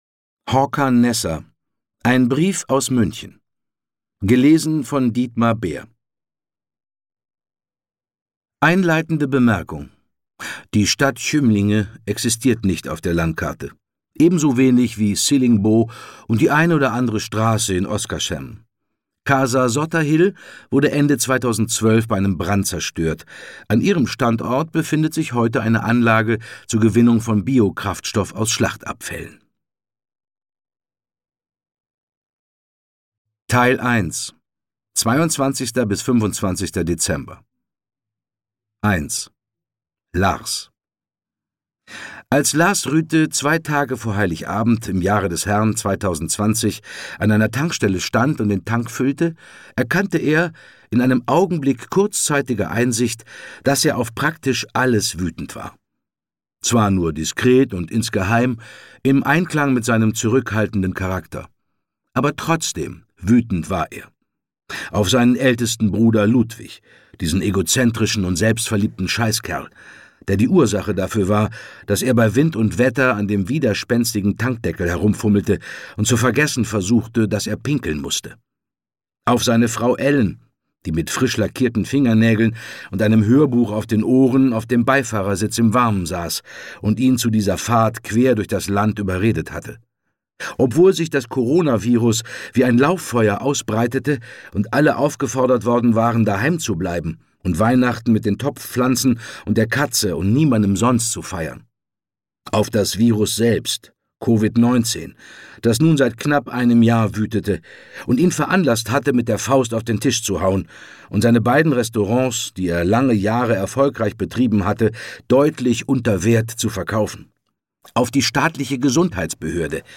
Dietmar Bär (Sprecher)
Ungekürzte Lesung mit Dietmar Bär